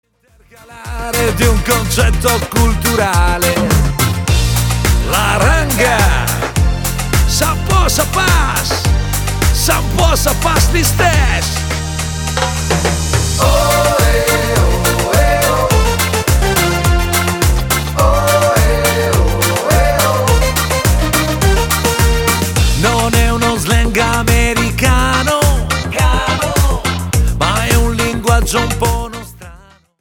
POP  (3.29)